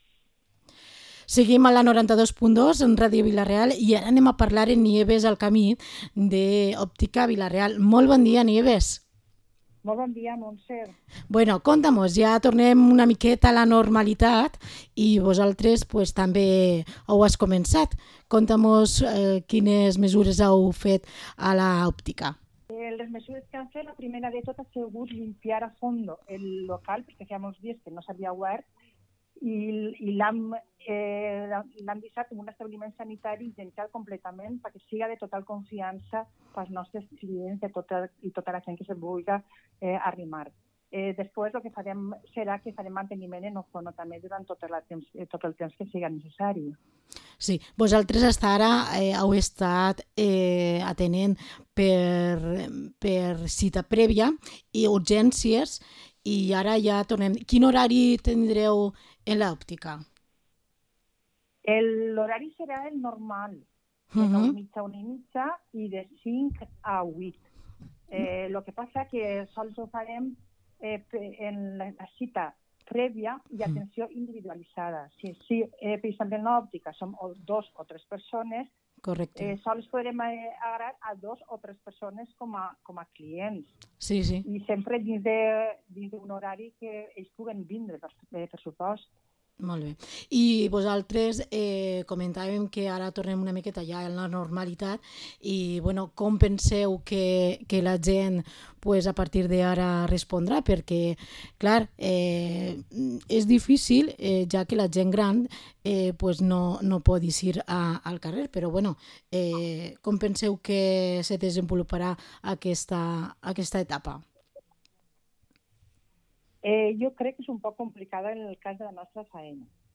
Entrevista a Óptica Vila-real